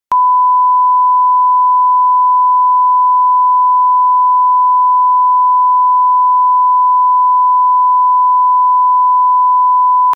1khz_left.mp3